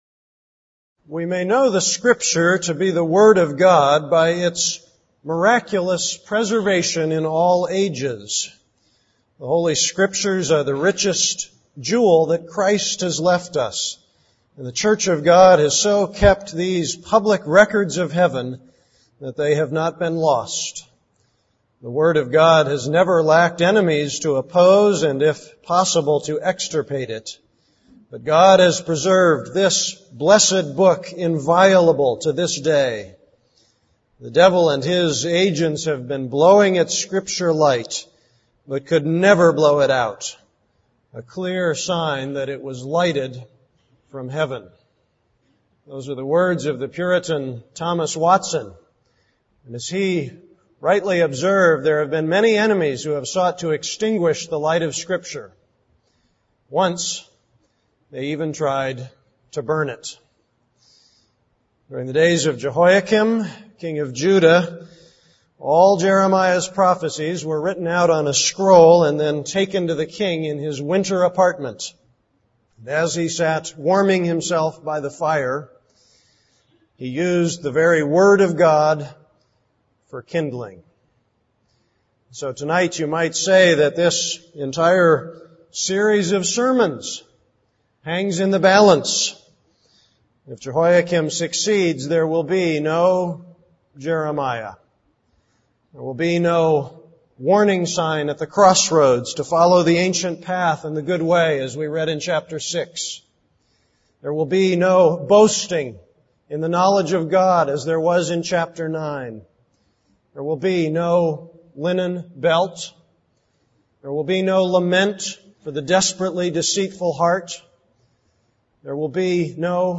This is a sermon on Jeremiah 36:1-22.